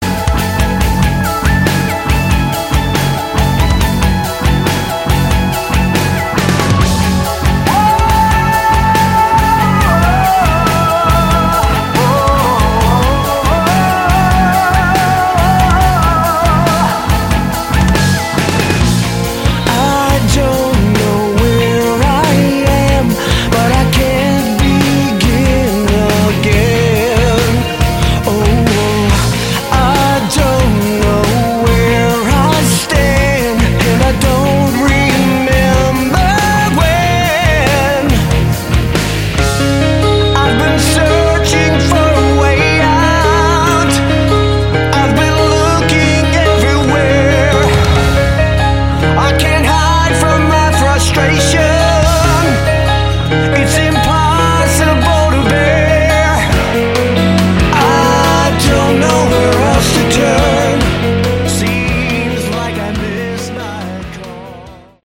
Category: AOR / Prog
guitars, bass, keyboards
vocals
drums